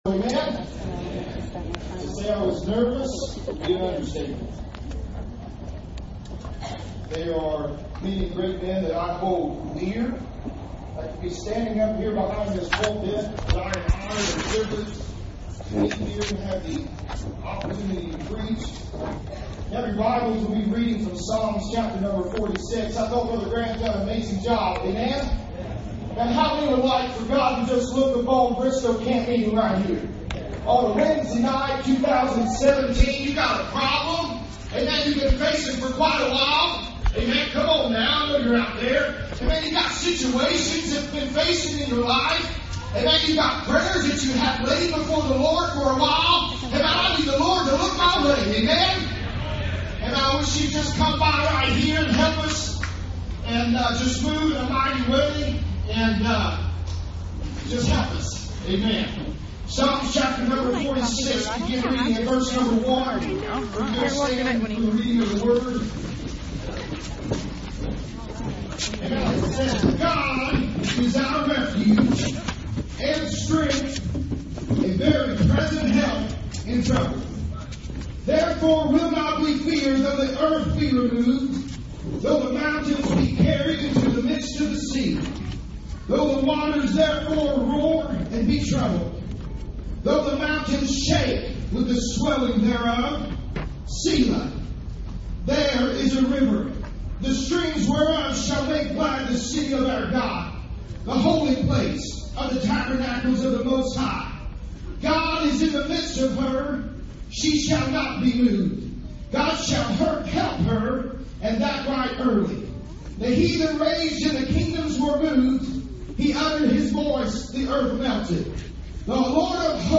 2017 Bristow Campmeeting - God Is My Refuge.mp3